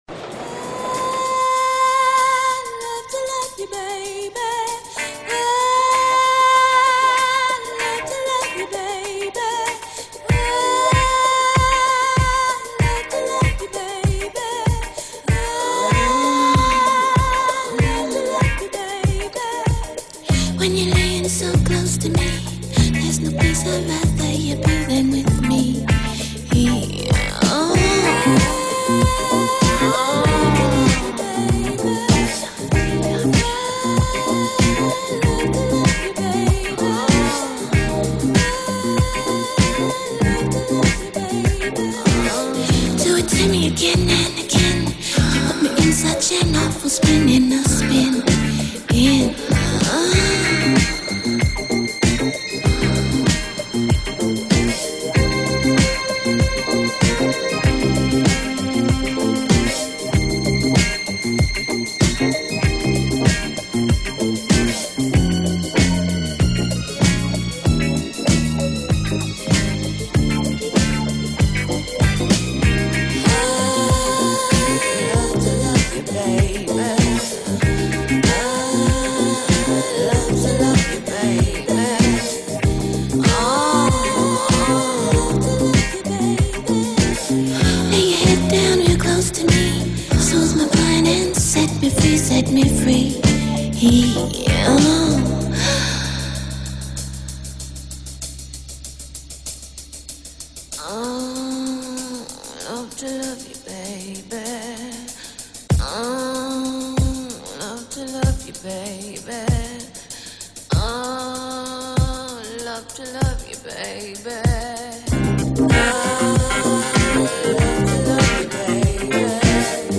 Filed under disco, electronica